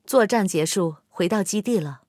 SU-122A战斗返回语音.OGG